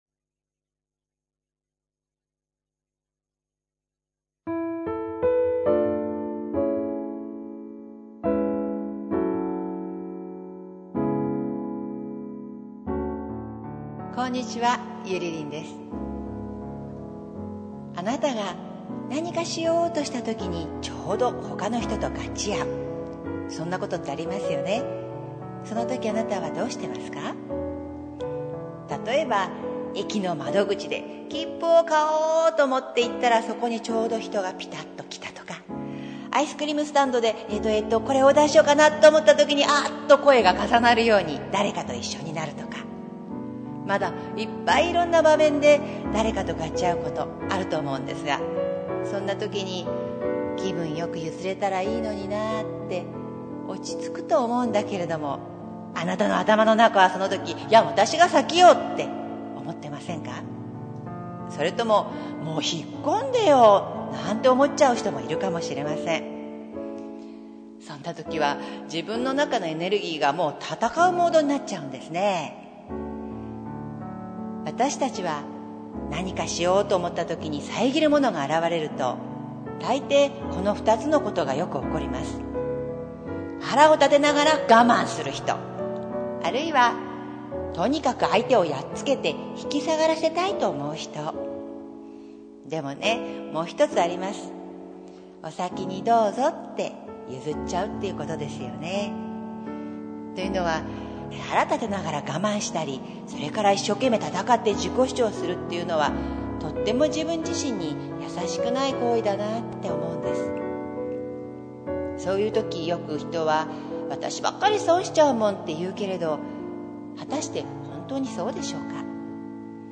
３分間フリートークです。